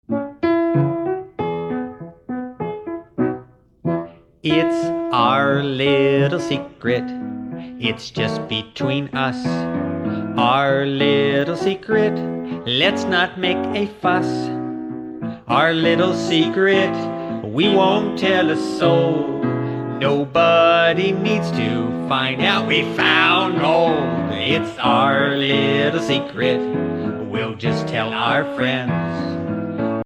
A Social Studies Musical
*  Catchy melodies, dumb jokes, interesting stories